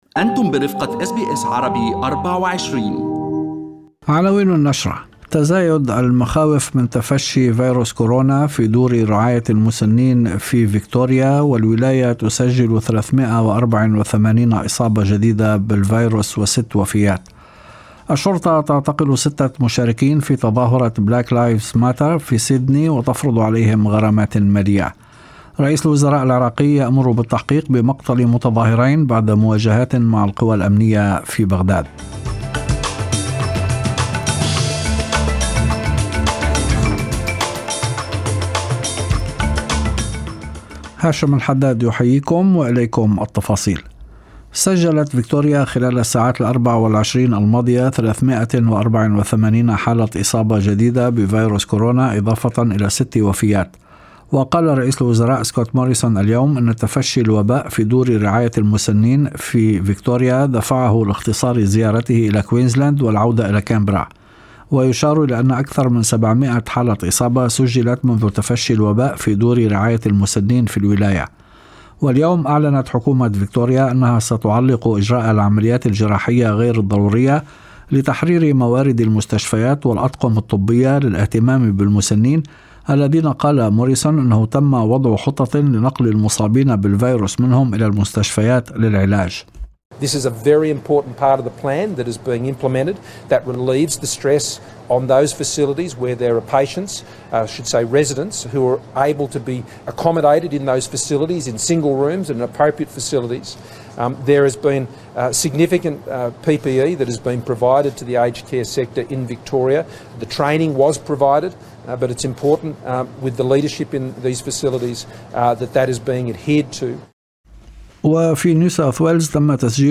نشرة أخبار المساء 28/07/2020